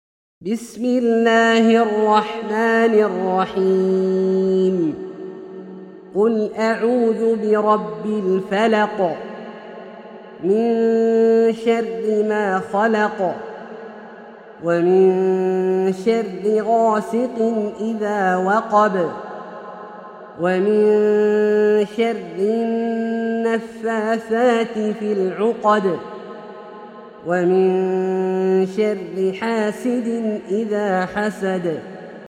سورة الفلق - برواية الدوري عن أبي عمرو البصري > مصحف برواية الدوري عن أبي عمرو البصري > المصحف - تلاوات عبدالله الجهني